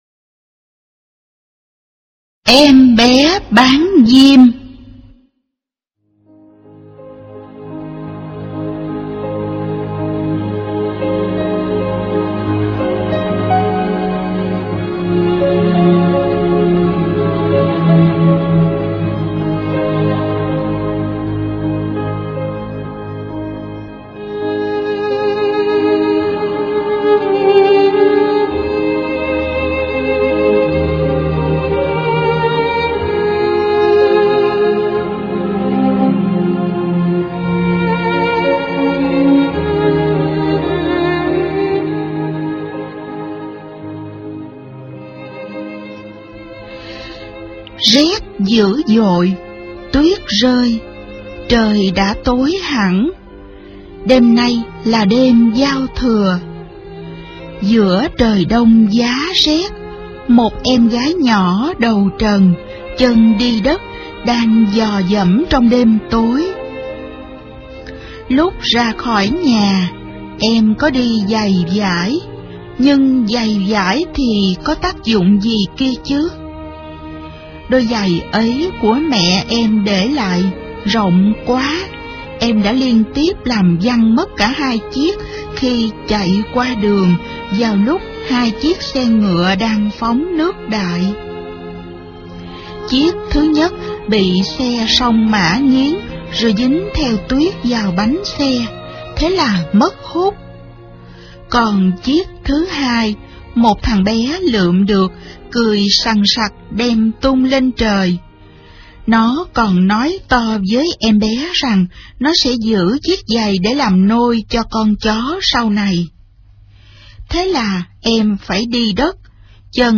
Sách nói | Cô bé bán diêm